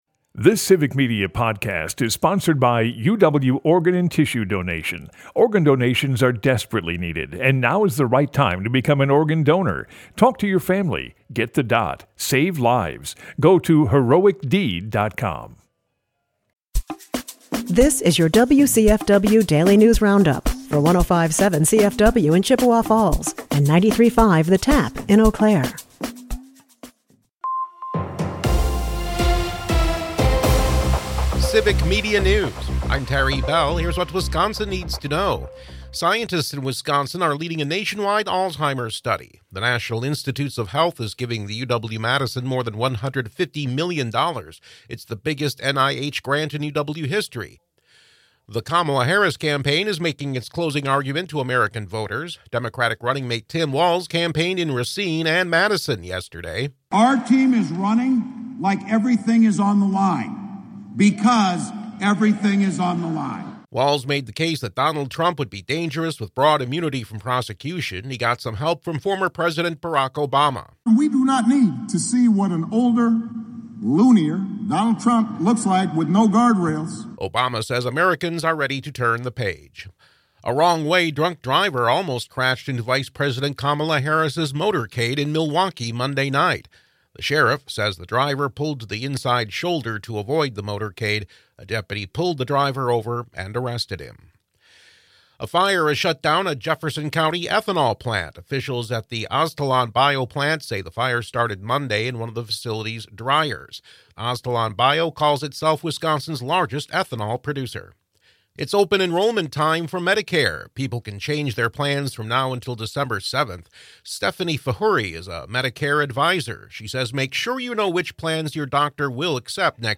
The WCFW Daily News Roundup has your state and local news, weather, and sports for Chippewa Falls, delivered as a podcast every weekday at 9 a.m. Stay on top of your local news and tune in to your community!